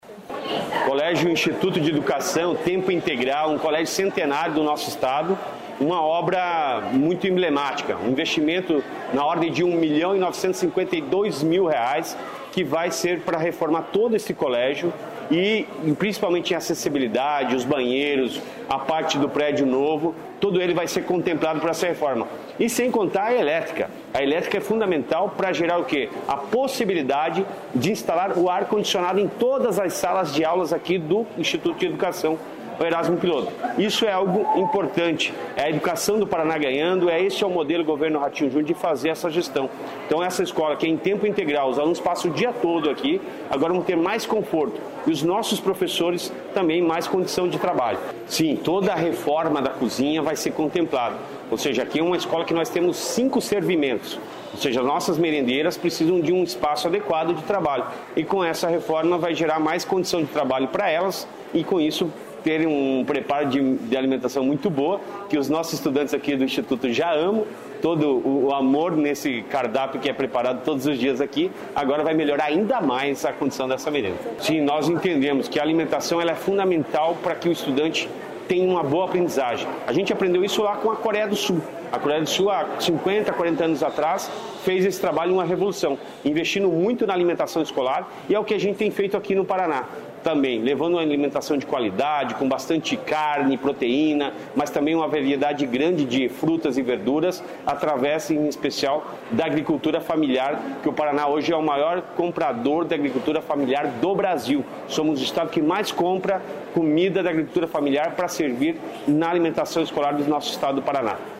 Sonora do secretário estadual de Educação, Roni Miranda, sobre reforma no Instituto de Educação do Paraná em Curitiba